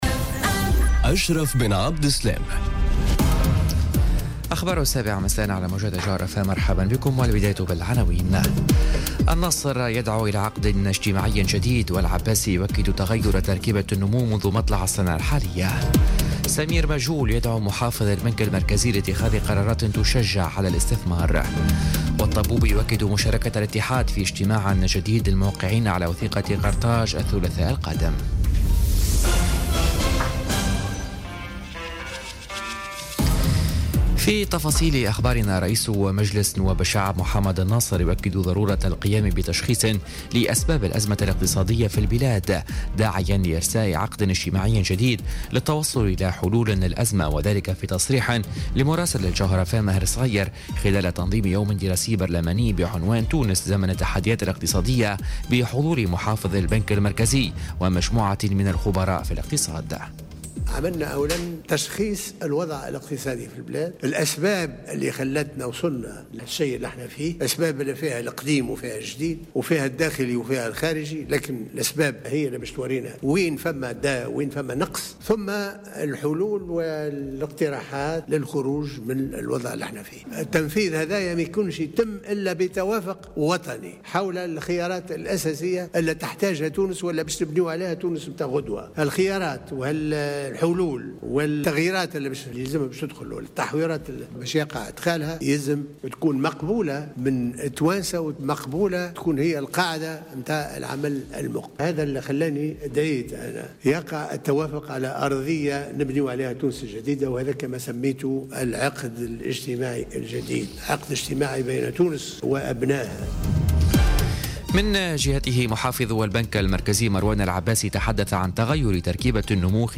نشرة أخبار السابعة مساءً ليوم السبت 10 مارس 2018